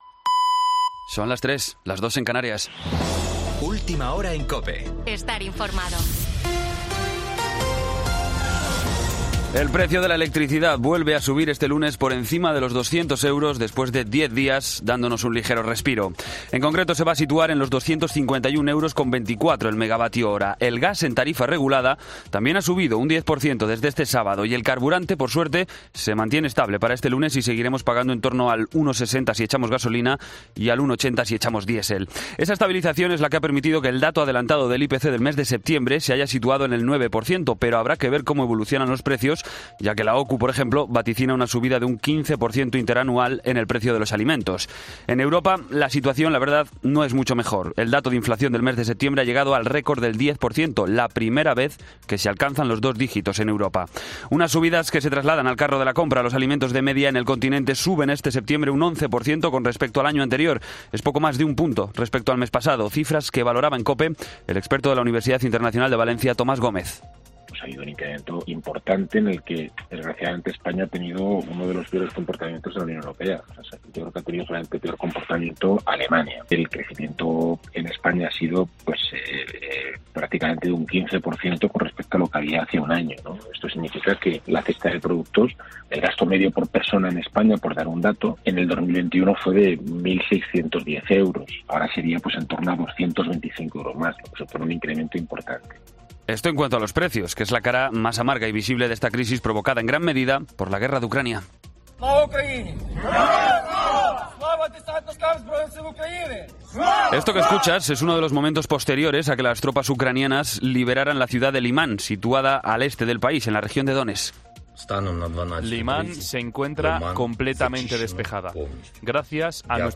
Boletín de noticias COPE del 03 de octubre a las 03:00 hora